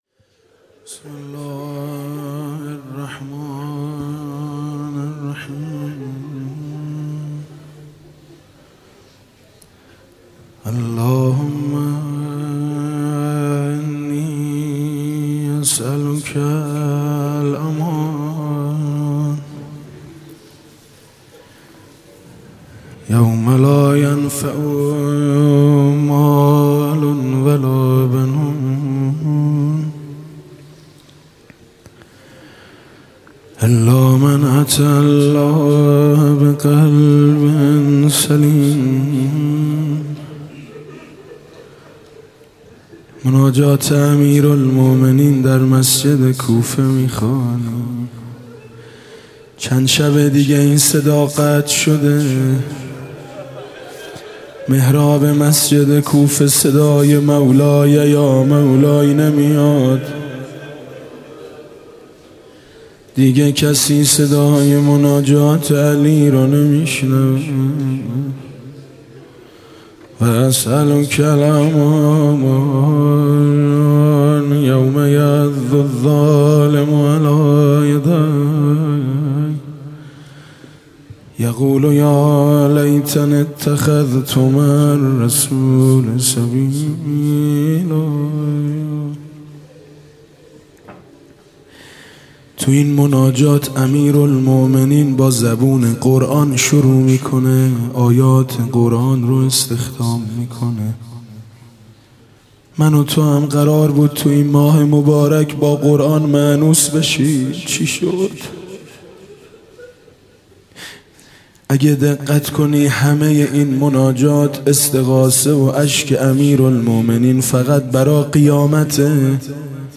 شب بیست و سوم رمضان 96 - هیئت شهدای گمنام - فرازهایی از مناجات امیرالمؤمنین علیه السلام